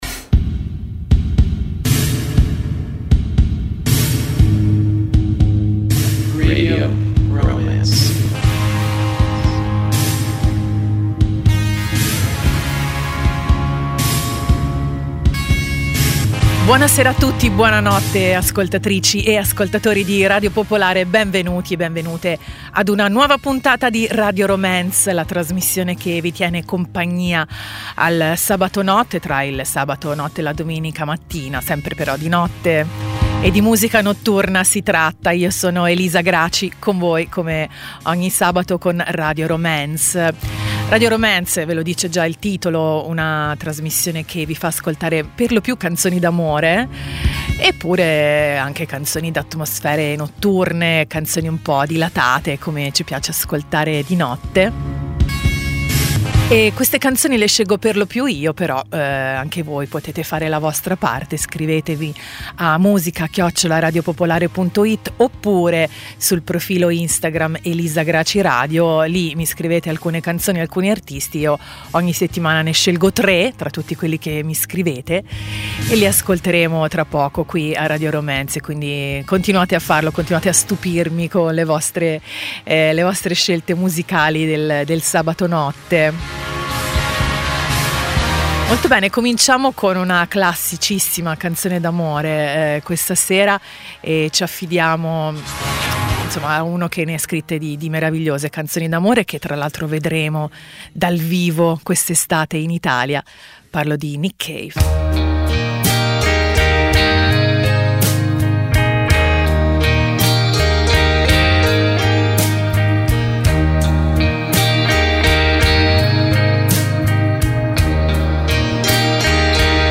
Canzoni d'amore, di desiderio, di malinconia, di emozioni, di batticuore.